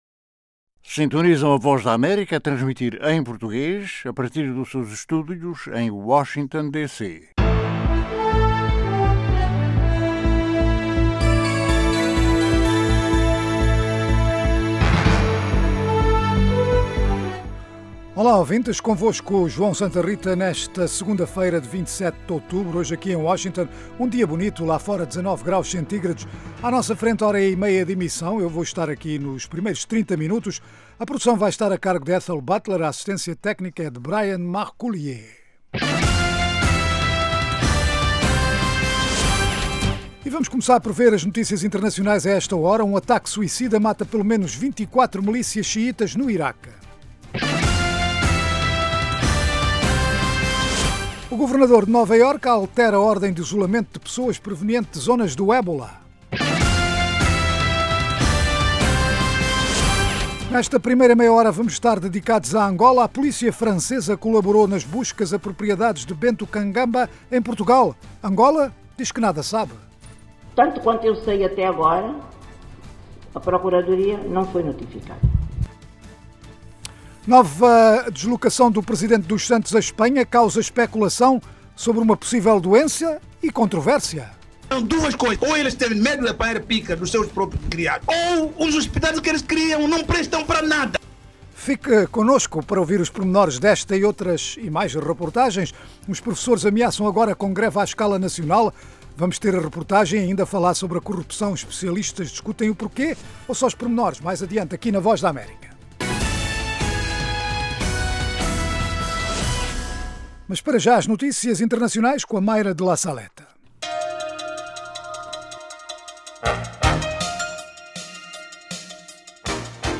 Um programa orientado aos países Lusófonos de África, sem esquecer o Brasil e comunidades de língua portuguesa noutras partes do mundo. Oferece noticias, informação e analises e divide-se em três meias-horas: 1) Orientado a Angola - com histórias enviadas de Angola, por jornalistas em todo o país sobre os mais variados temas. 2) Notícias em destaque na África lusófona e no mundo, 3) Inclui as noticias mais destacadas do dia, análises, artes e entretenimento, saúde, questões em debate em África.